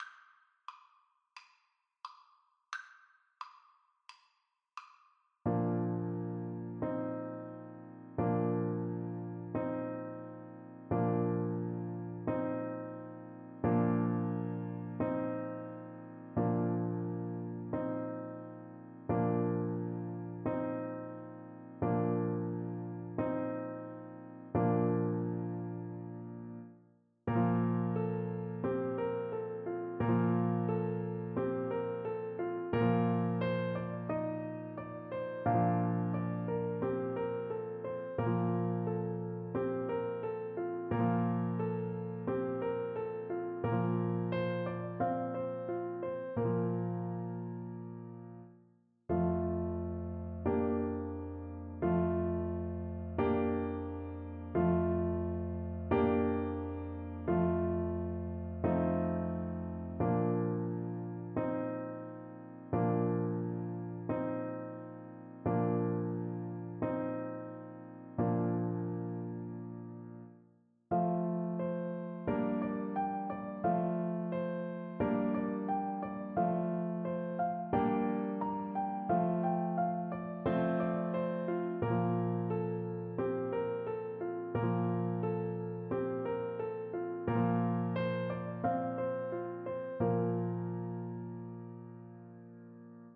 4/4 (View more 4/4 Music)
Moderato = c. 88
Classical (View more Classical French Horn Music)